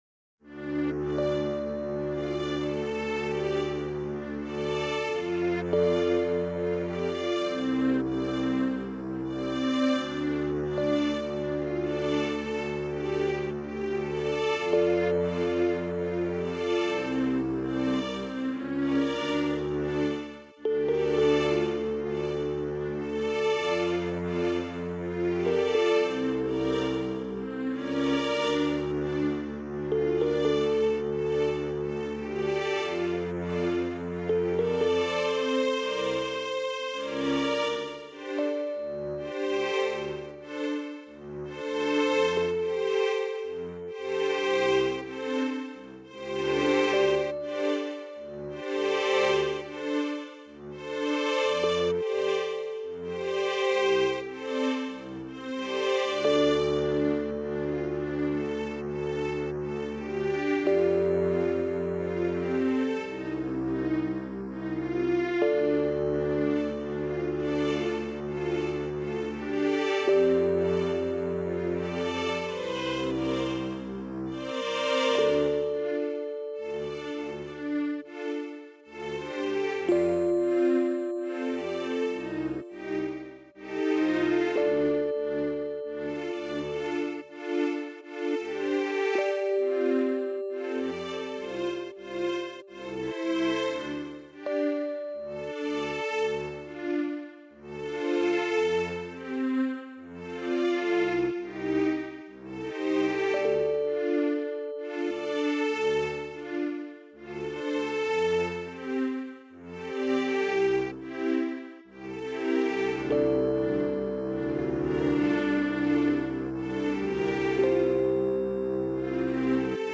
Rpg Strings With Electric Piano
rpgstringsandelectricpiano_0.ogg